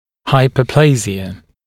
[ˌhaɪpəu’pleɪʒɪə][ˌхайпоу’плэйжиэ]гипоплазия